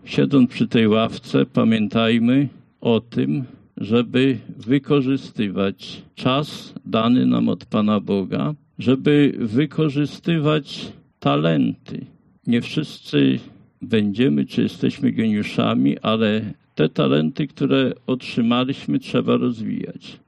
Tak Szkoła Podstawowa w Goworowie obchodziła wczoraj swój złoty jubileusz 50-lecia nadania imienia Mikołaja Kopernika.